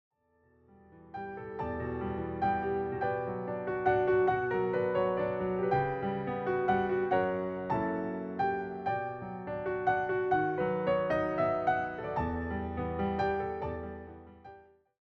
all reimagined through solo piano.